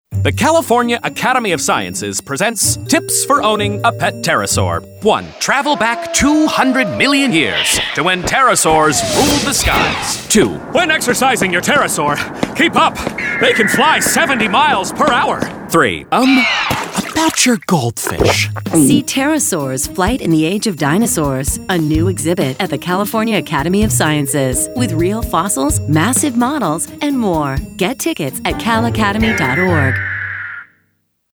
RADIO: "MY PET PTEROSAUR"